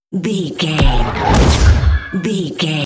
Sci fi shot whoosh to hit
Sound Effects
dark
futuristic
intense
woosh to hit